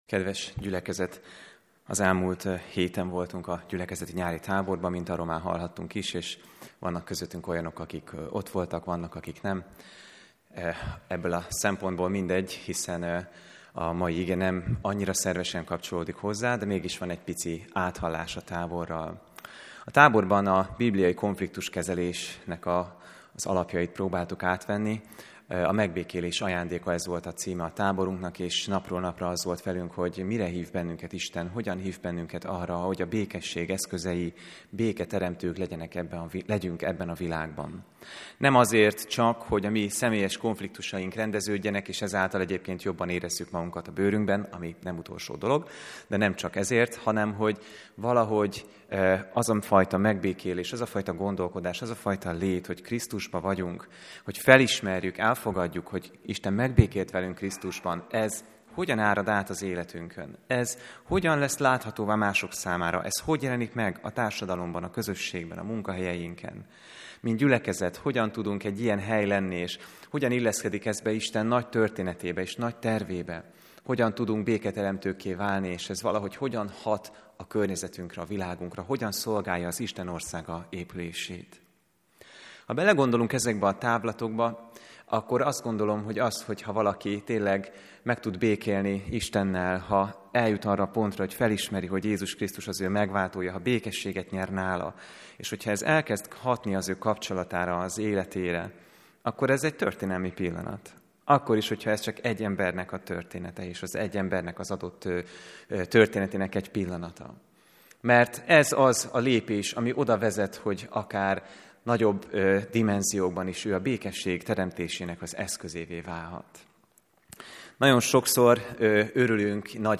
AZ IGEHIRDETÉS MEGHALLGATÁSA